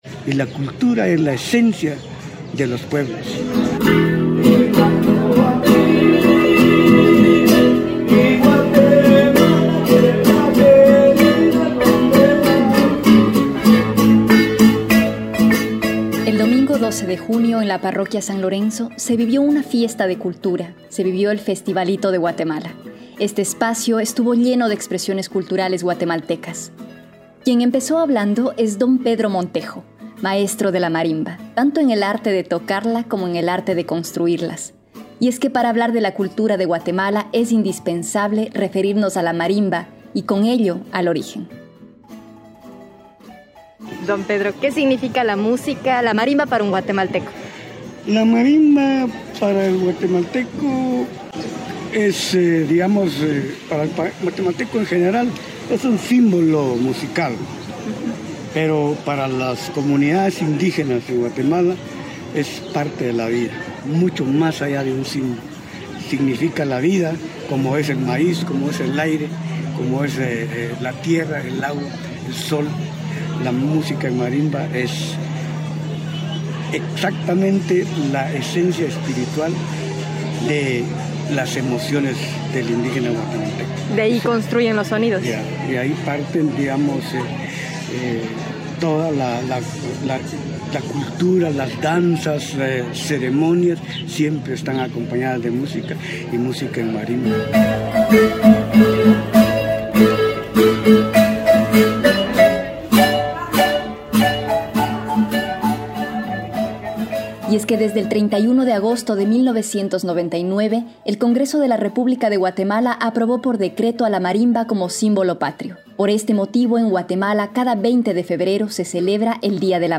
Eso fue lo que demostró la comunidad Guatemalteca el día de domingo en el Festivalito que destacó sus tradiciones.
La gente hablaba, reía a carcajadas y unos aprovechaban para comer o bailar al ritmo de la tradicional marimba (y otros ritmos que tampoco se hicieron esperar).